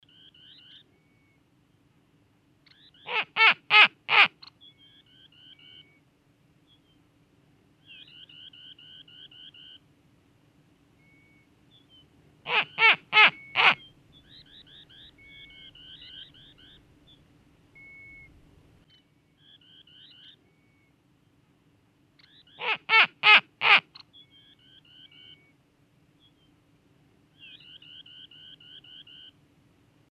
Kuuntele: Tunturipöllö
Tunturipöllöt vaeltavat pitkin Jäämeren rannikkotundraa ja Fennoskandian tunturiylänköjä sen mukaan, mistä ne löytävät tarpeeksi sopuleita pesiäkseen. Kuuntele tunturipöllön ääninäyte!